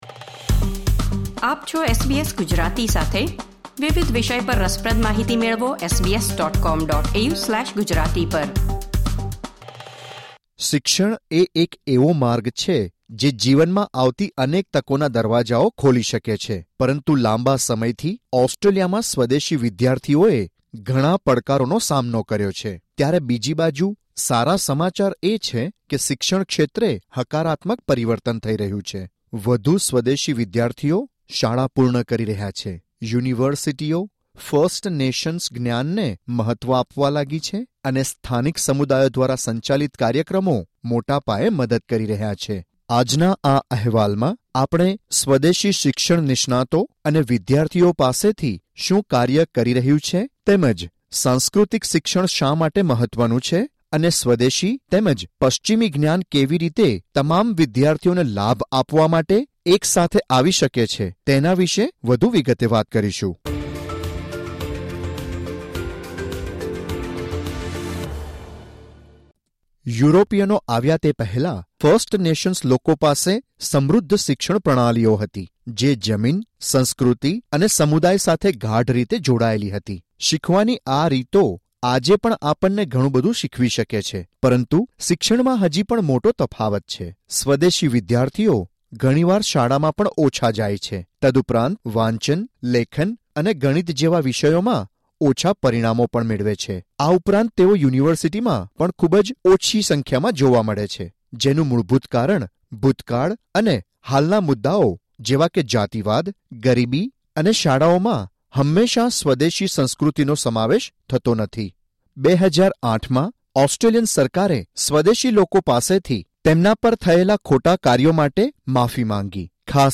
In this episode we’ll hear from Indigenous education experts and students about what’s working, why cultural education matters and how Indigenous and Western knowledge can come together to benefit all students.